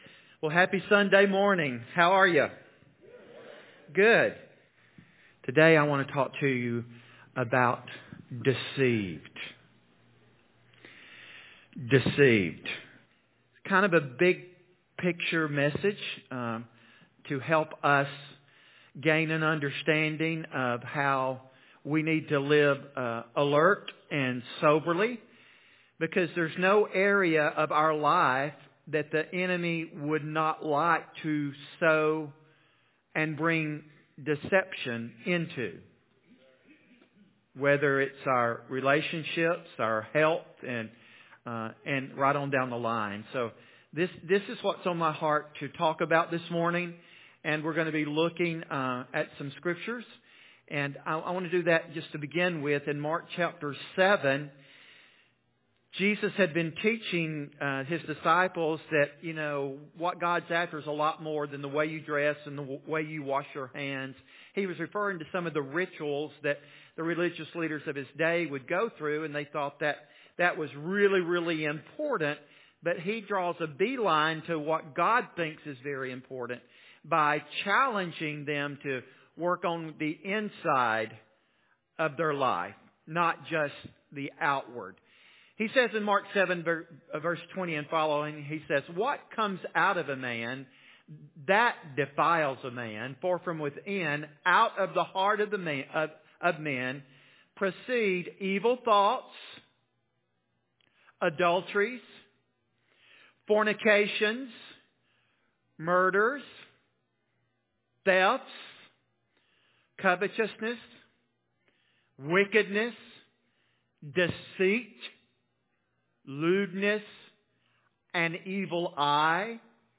2023 Current Sermon Deceived